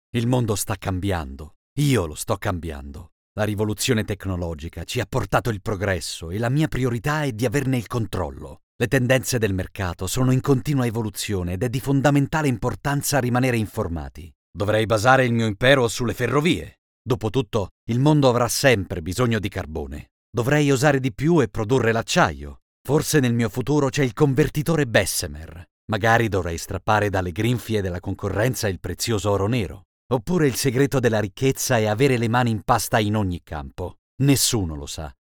Ho una voce calda, profonda, versatile e dinamica!
Sprechprobe: Industrie (Muttersprache):
I have a warm, deep, versatile and dynamic voice!
Autorevole Fiducioso, Esperto, Potente.mp3